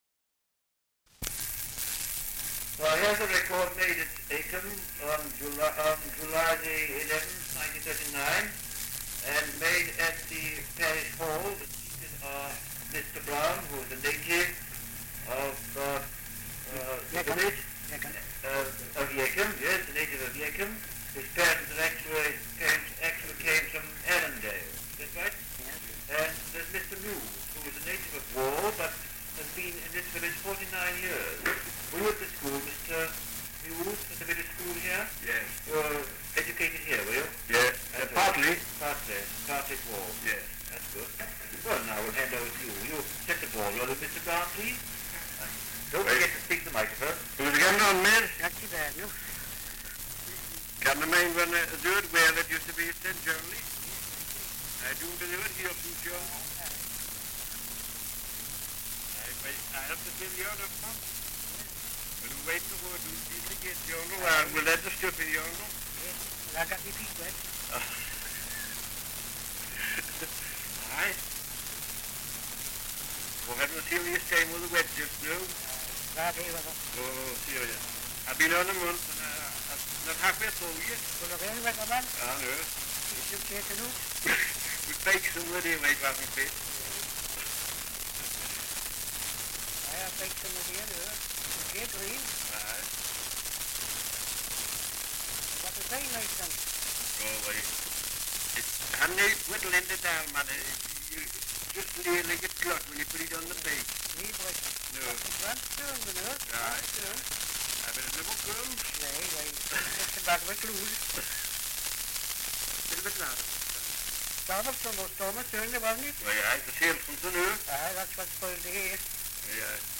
Dialect recording in Acomb, Northumberland
78 r.p.m., cellulose nitrate on aluminium